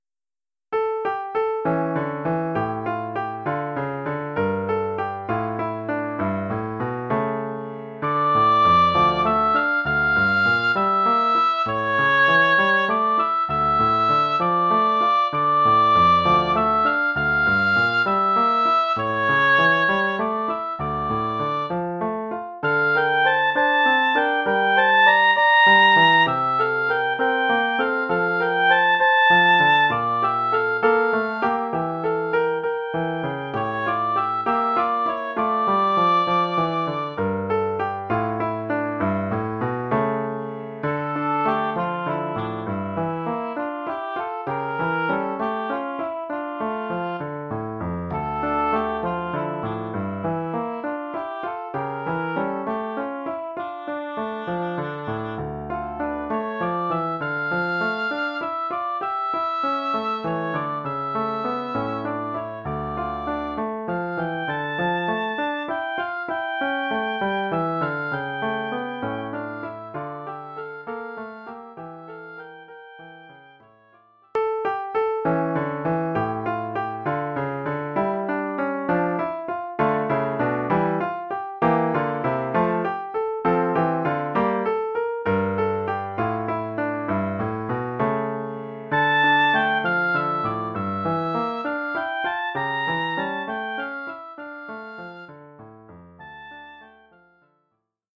Oeuvre pour hautbois et piano.